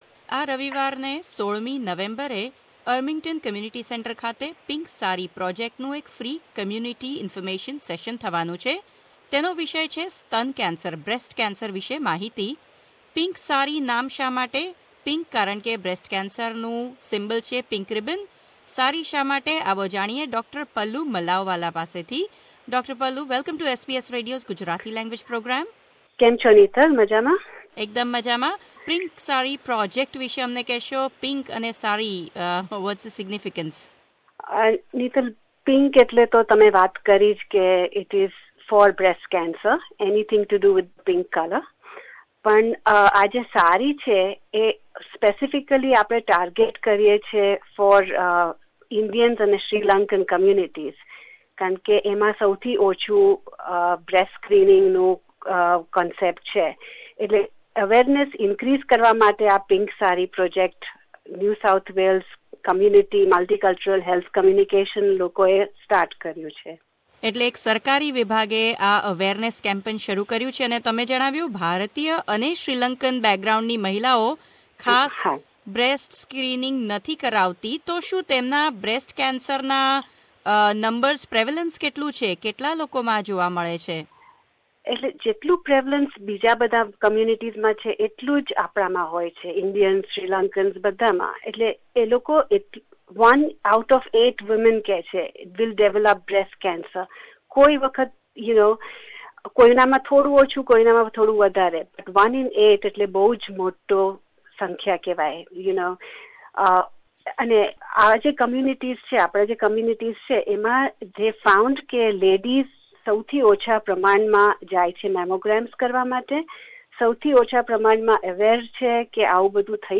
વાતચીત